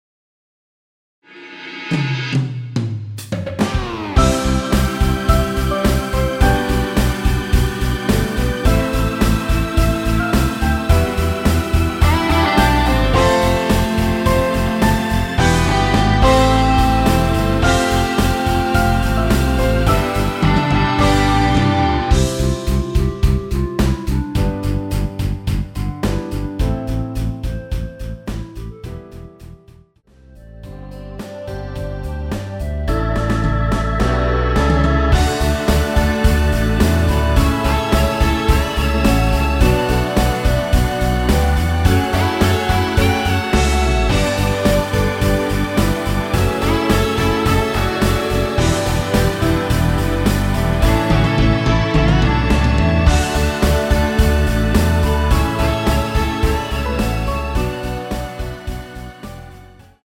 멜로디 MR이란
앞부분30초, 뒷부분30초씩 편집해서 올려 드리고 있습니다.
중간에 음이 끈어지고 다시 나오는 이유는